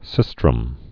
(sĭstrəm)